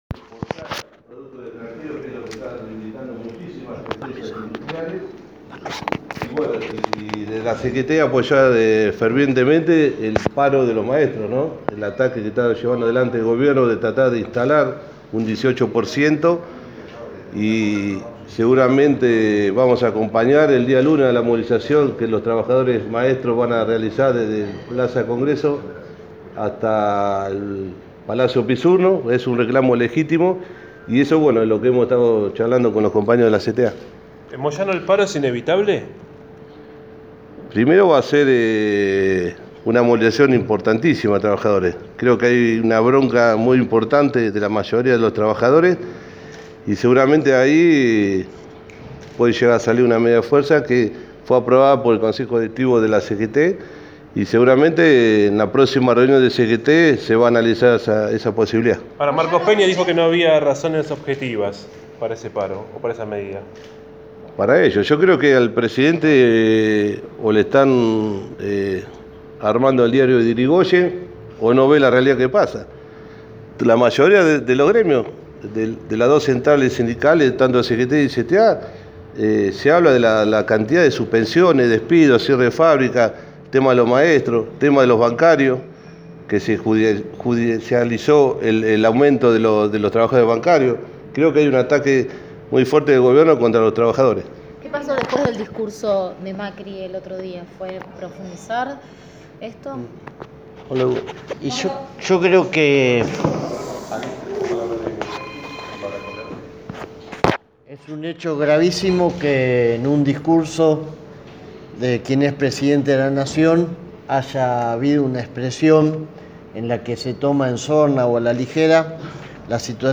El Secretario Gremial de la CGT, Pablo Moyano, junto a los dos titulares de la CTA, Hugo Yasky y Pablo Micheli, ofrecieron una conferencia de prensa en la que detallaron las alternativas de la movilización que organiza la Confederación General del Trabajo, el 7 de marzo, en el Ministerio de Producción.
Conferencia-de-Prensa-CTA-CGT.mp3